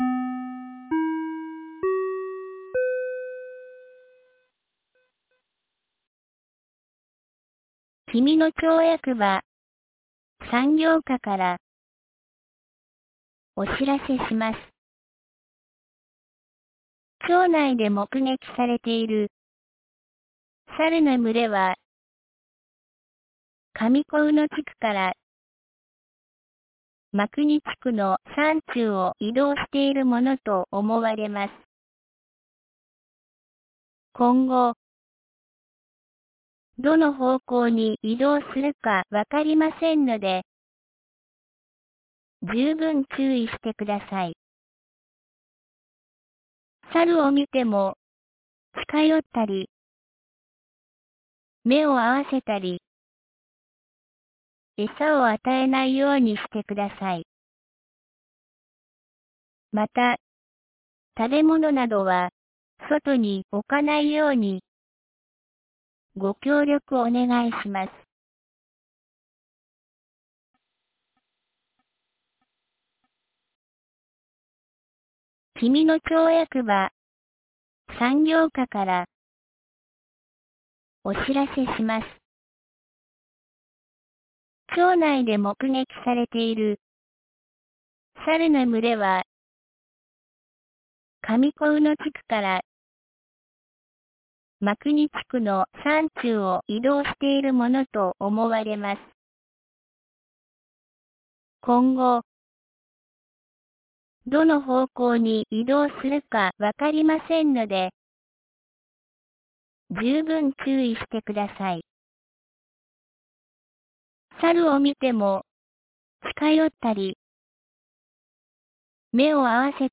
2025年08月13日 17時07分に、紀美野町より全地区へ放送がありました。